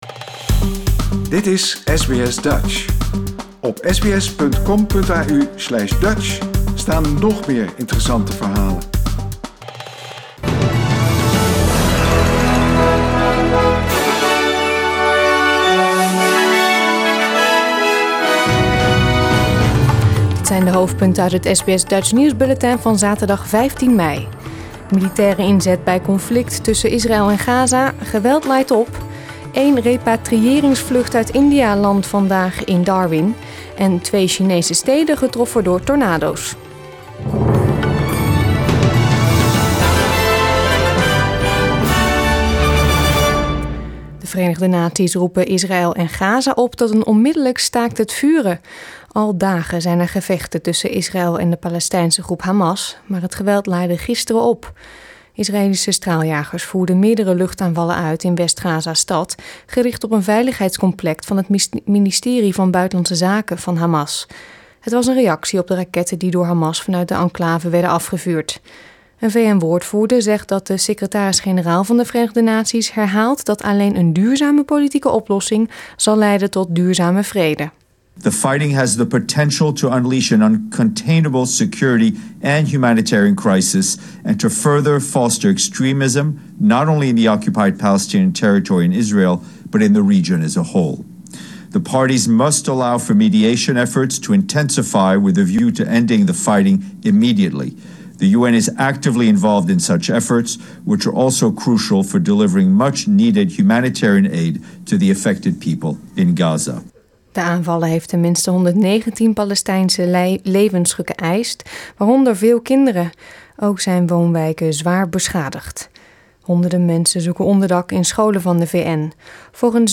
Nederlands/Australisch SBS Dutch nieuwsbulletin van zaterdag 15 mei 2021